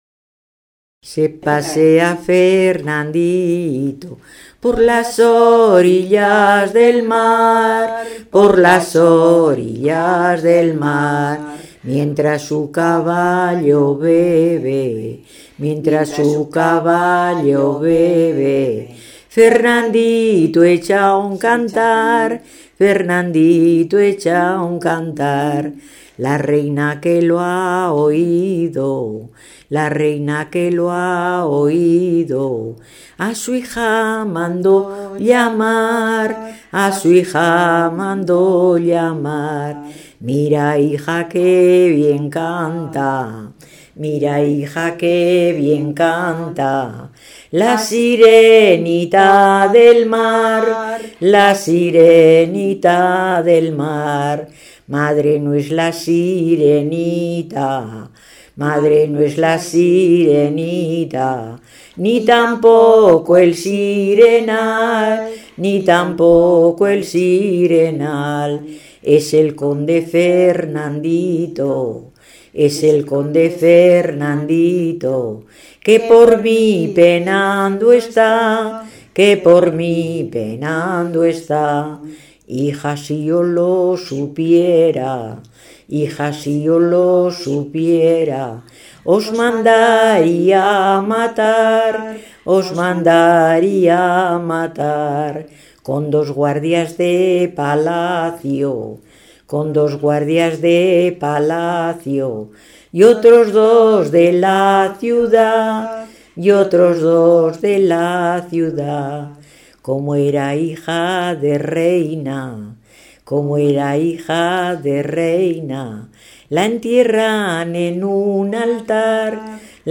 Clasificación: Romancero
Localidad: Villamediana de Iregua
Lugar y fecha de recogida: Logroño, 16 de julio de 2001